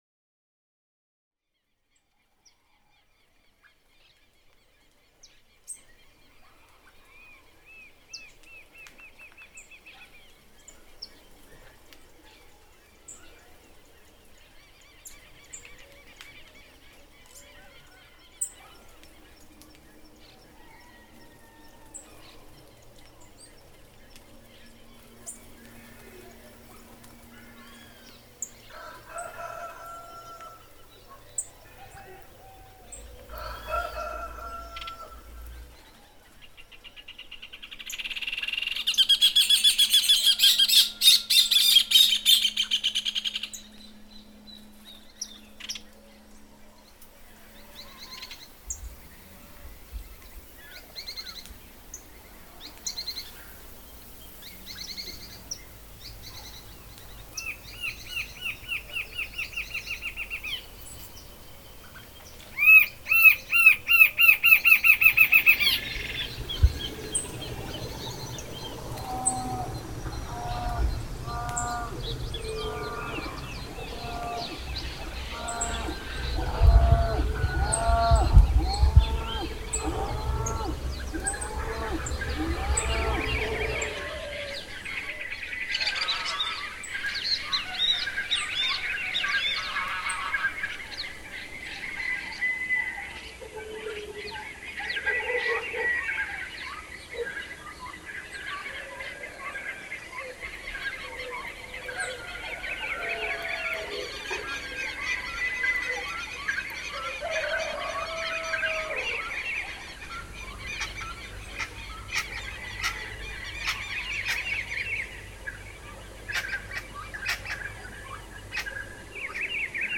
PAISAJE SONORO
MP3-Paisaje-Sonoro-Escucha-colectiva.mp3